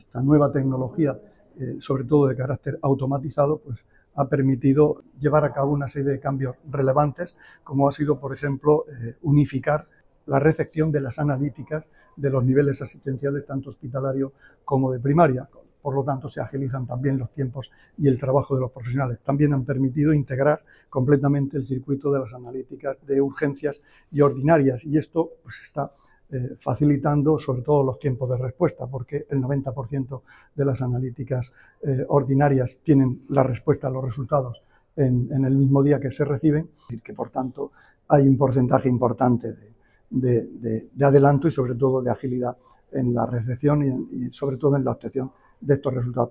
Declaraciones del consejero de Salud, Juan José Pedreño, durante su visita al nuevo laboratorio del hospital Morales Meseguer.